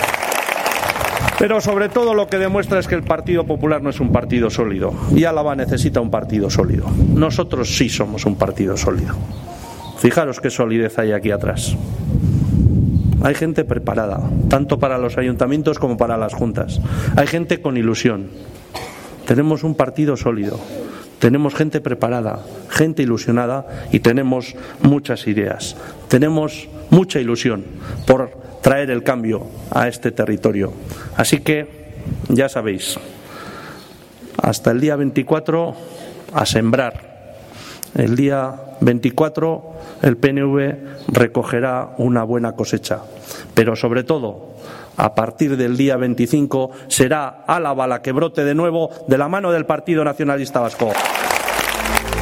Andoni Ortuzar en la presentación de las candidaturas municipales de Rioja Alavesa (3)